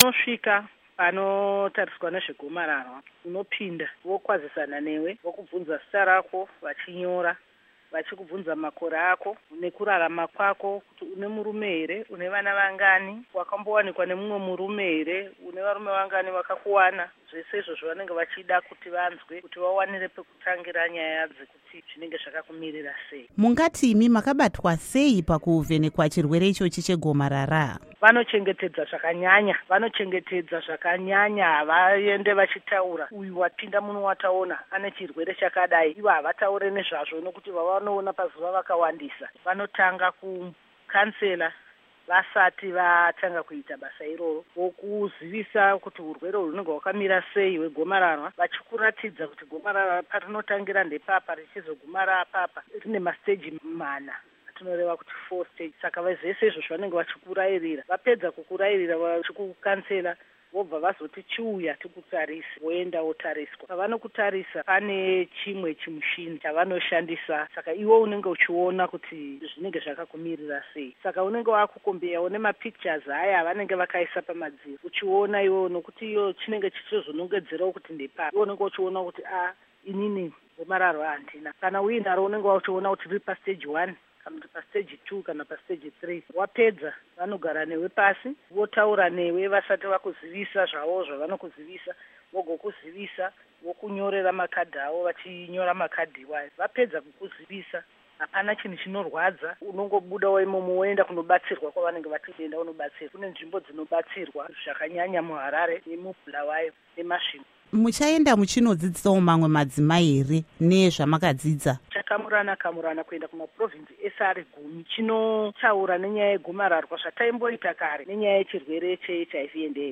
Hurukuro NaAmai Rorana Muchihwa